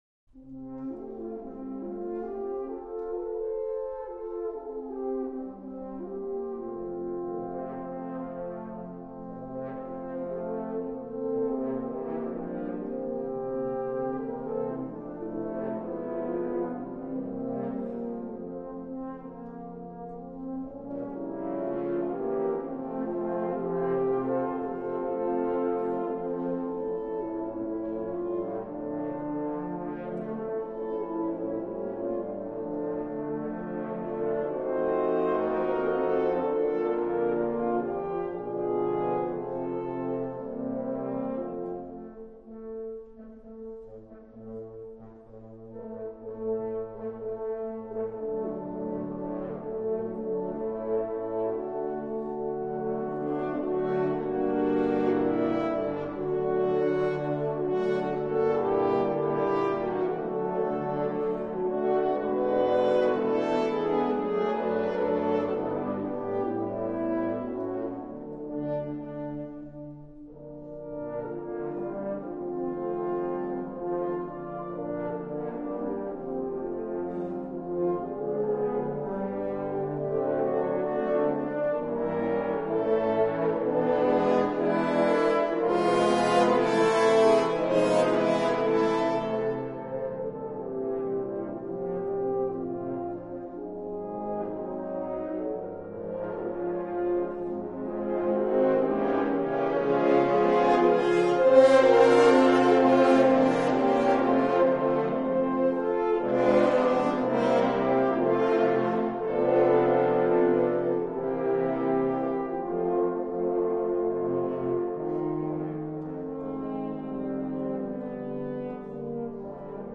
東京ウィンナホルン協会　第１回演奏会
三鷹市芸術文化センター風のホール
<< Mitaka City Arts Center >>